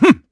Riheet-Vox_Attack6_kr.wav